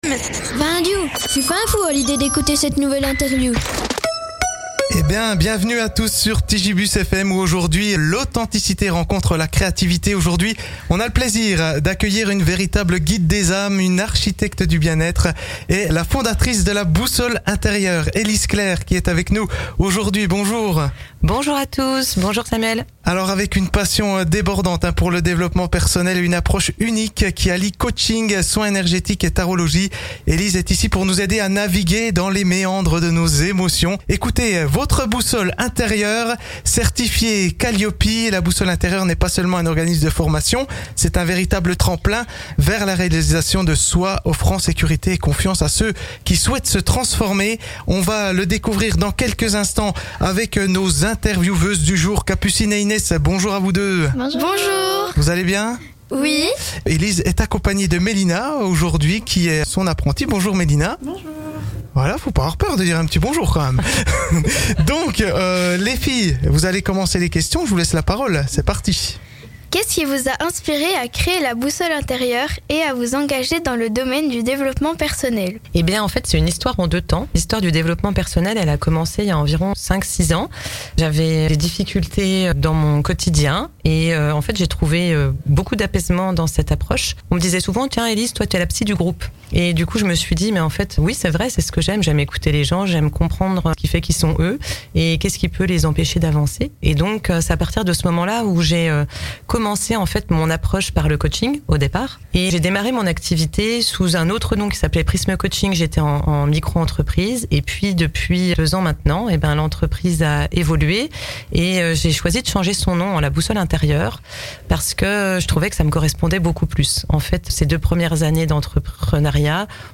Interview P'tit Gibus FM